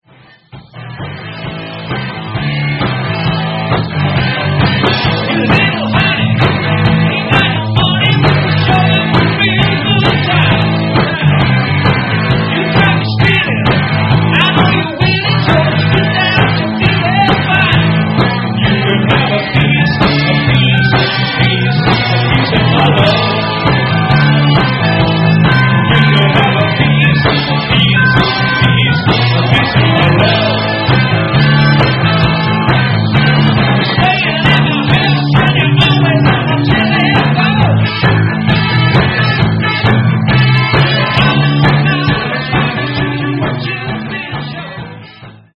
Rehearsals London '81